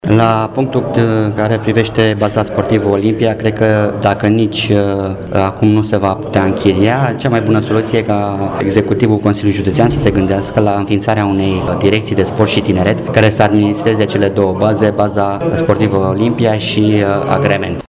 Consilierul judeţean Dragoş Crăciun, preşedintele Comisie pentru Tineret şi Sport, a făcut o propunere în cadrul plenului, sperând în susţinerea şi materializarea ei: